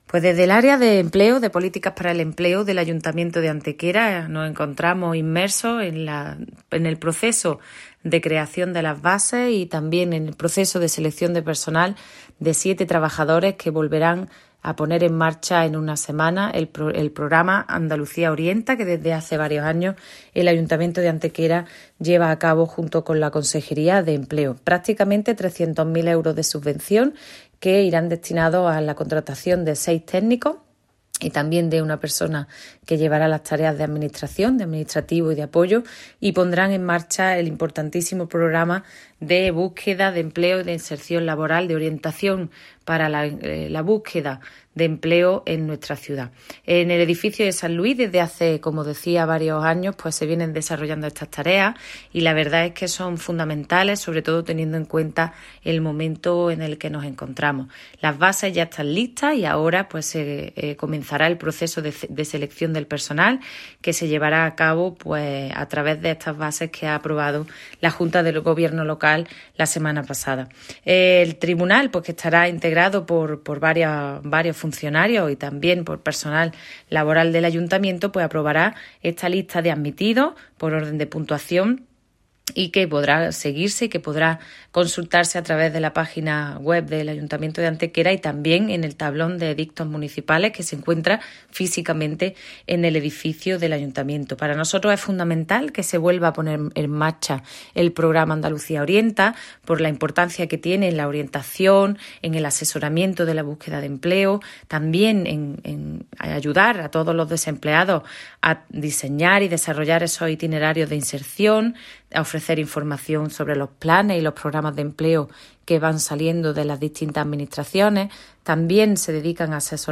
La teniente de alcalde delegada de Políticas de Empleo, Ana Cebrián, informa de la reciente aprobación de las bases para la selección, mediante concurso de méritos, se seis técnicos de orientación profesional para el empleo y un personal administrativo de apoyo para la ejecución de un nuevo programa de Andalucía Orienta, subvencionado con 291.196,64 euros por la Consejería de Empleo, Formación y Trabajo Autónomo de la Junta de Andalucía.
Cortes de voz